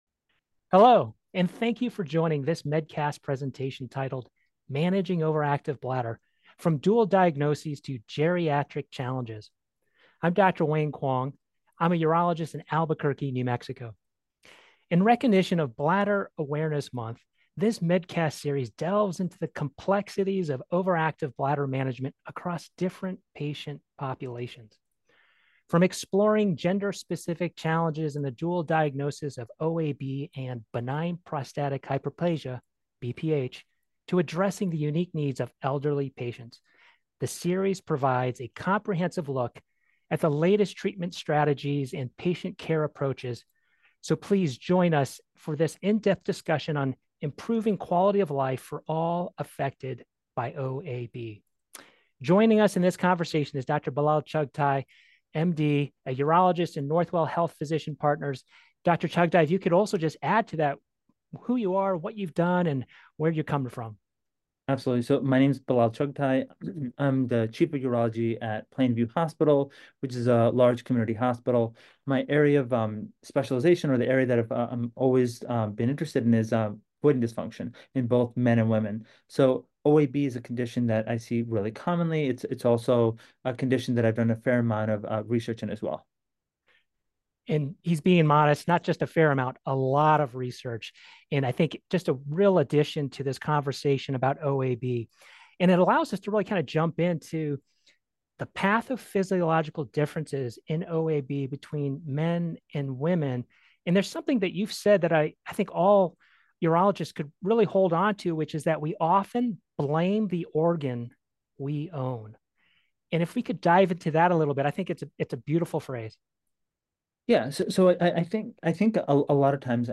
Experts discuss overactive bladder and the impacts on quality of life, distinguishing OAB from BPH, and how a delayed diagnosis can worsen symptoms.